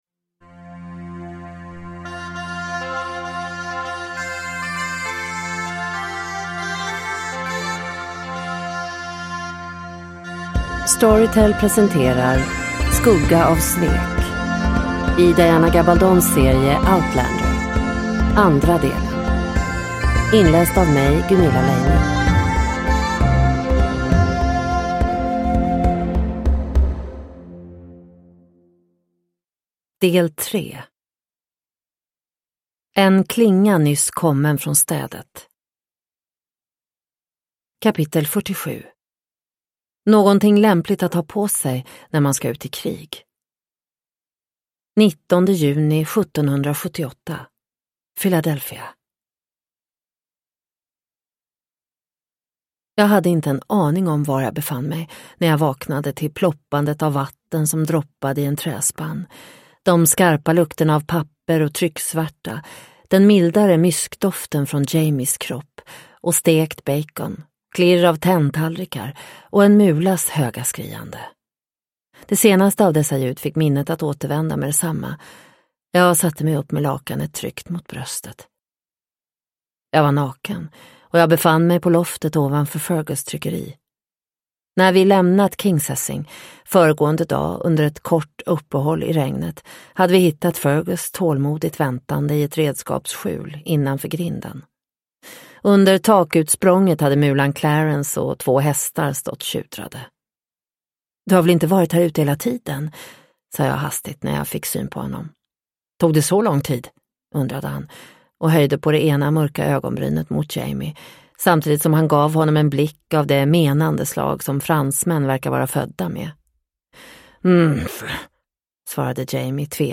Skugga av svek - del 2 – Ljudbok – Laddas ner